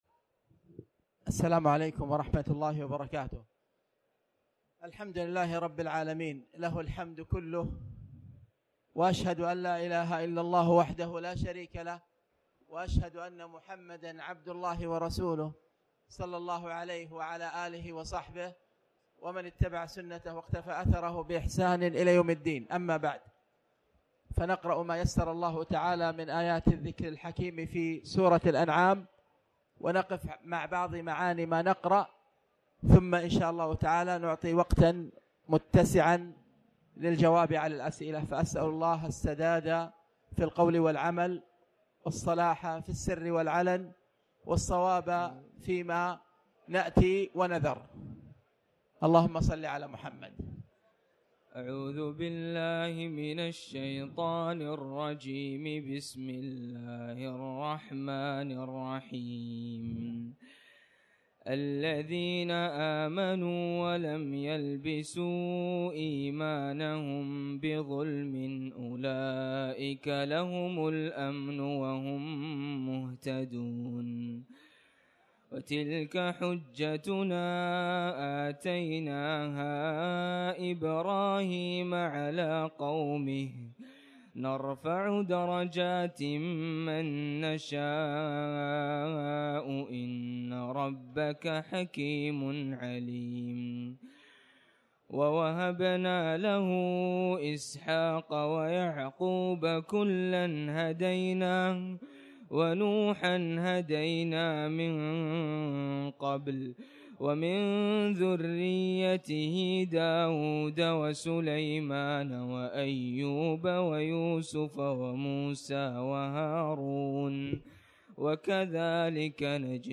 تاريخ النشر ٢٧ رمضان ١٤٣٩ هـ المكان: المسجد الحرام الشيخ